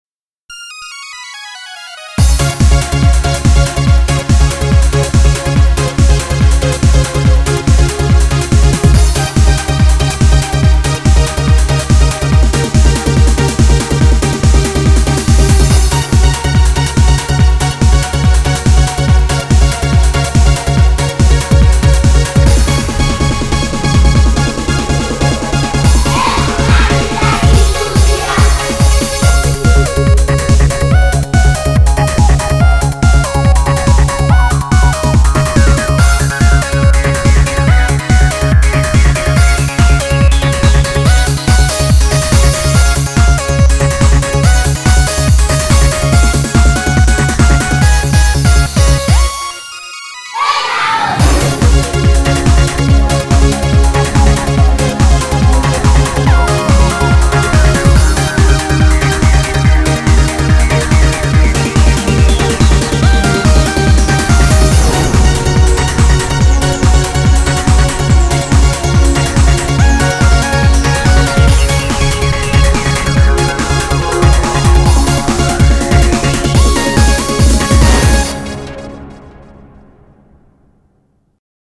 BPM142
Audio QualityPerfect (High Quality)
Genre: TRANCE.